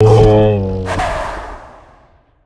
chimera_death_2.ogg